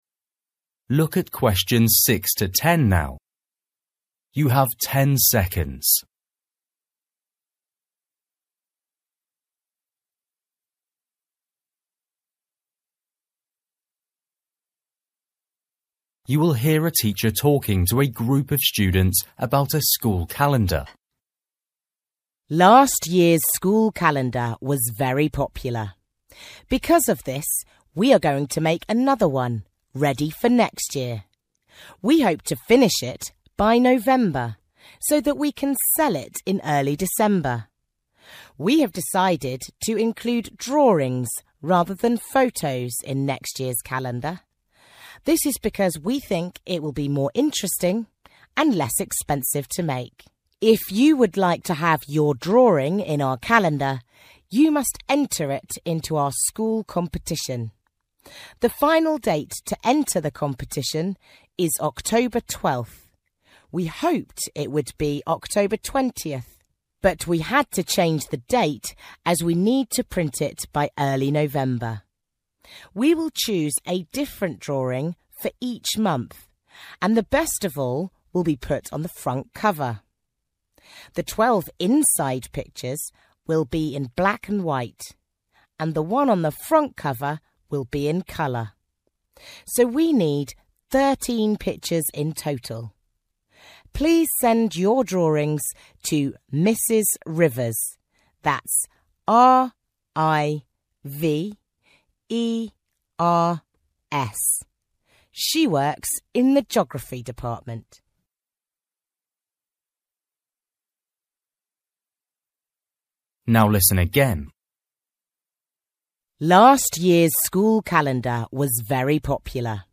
You will hear a teacher talking to a group of students about a school calendar.